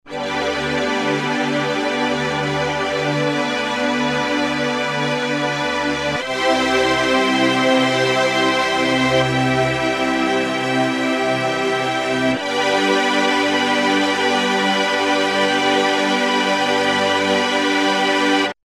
Vintage keys (model 9045) is an ffordable rackmount expander packed with samples from famous vintage keyboards and synths.
demo water skies